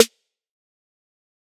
Start It Up Snare 2.wav